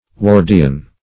Wardian \Ward"i*an\, a.